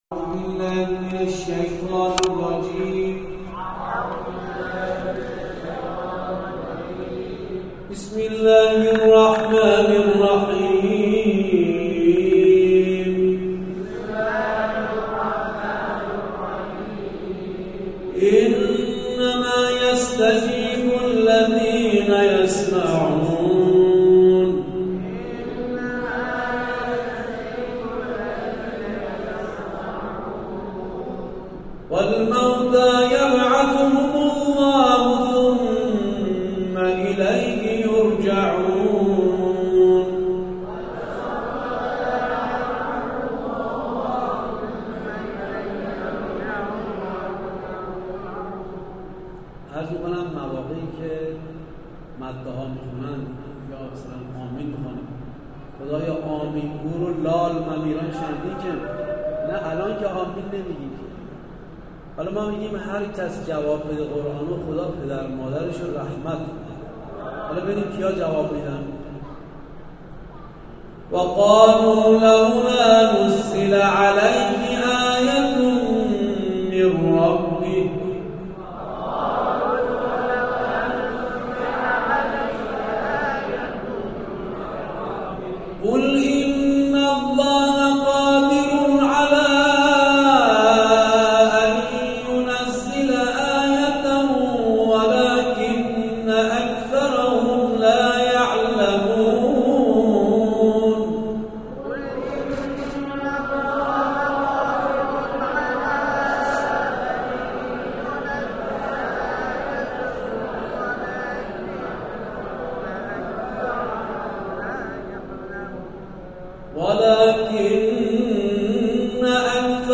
جمع‌خوانی